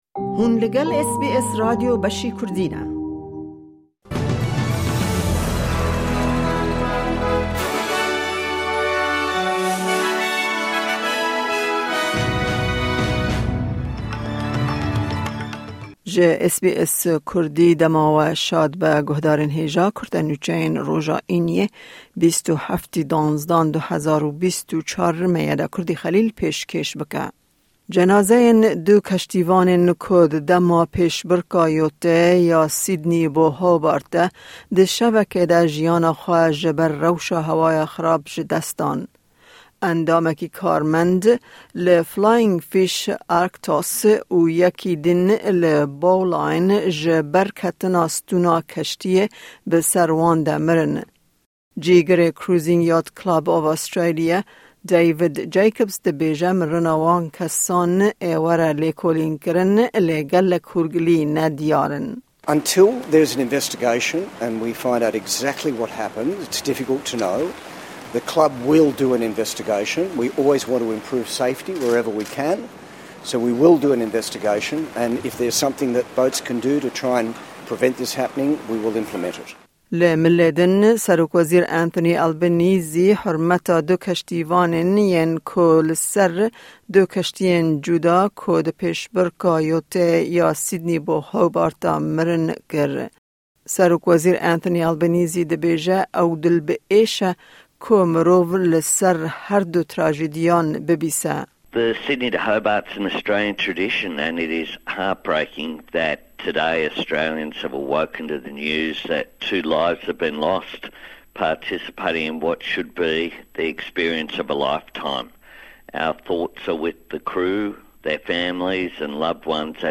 Kurte Nûçeyên roja Înî 27î Kanûna 2024